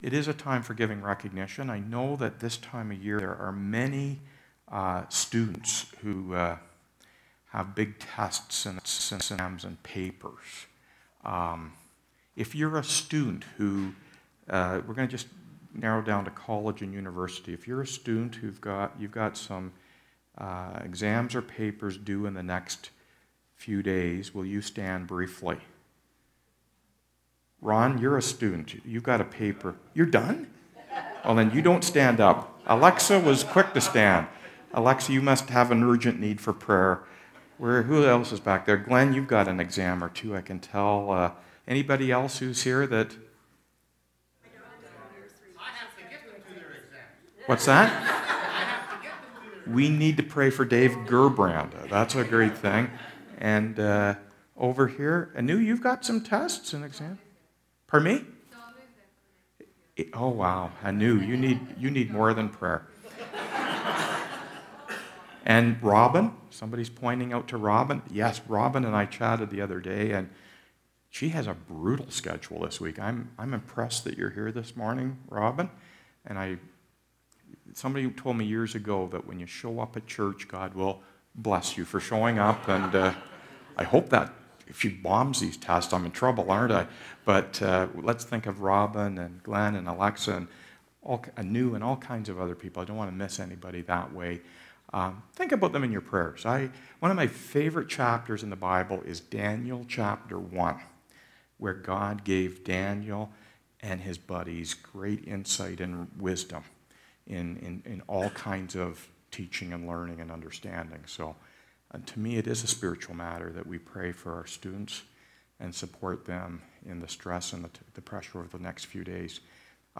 Past Sermons - Byron Community Church